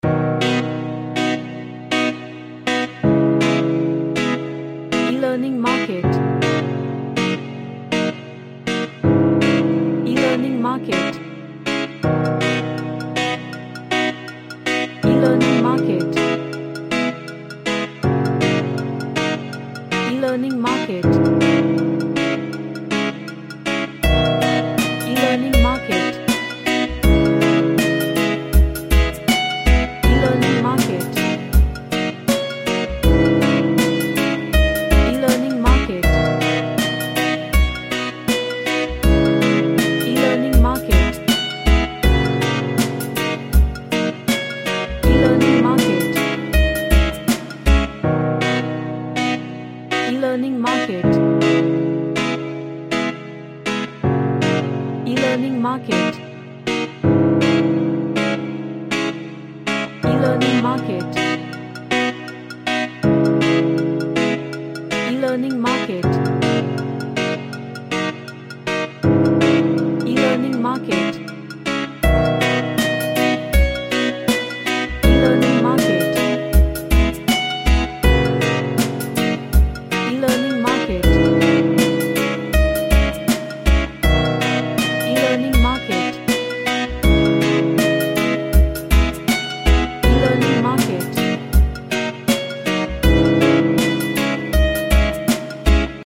A reggae track with happy and bouncy vibes.
Funky